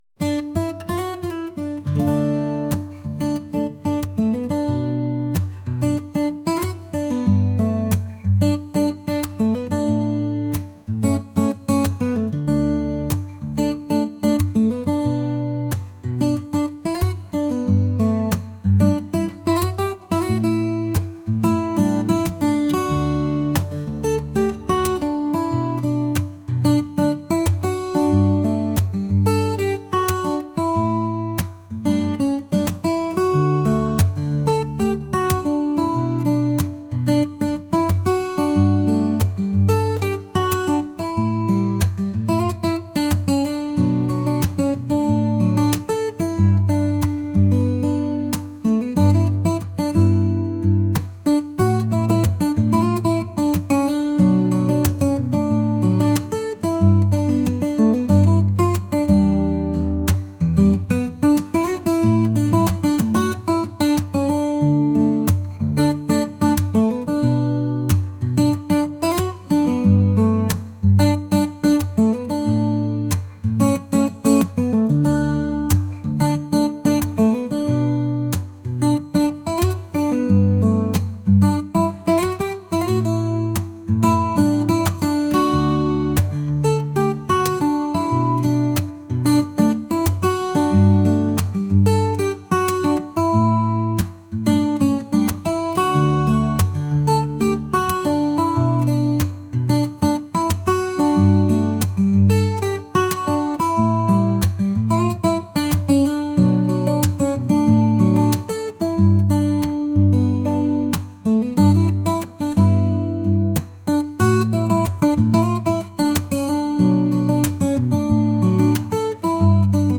acoustic | pop | soul & rnb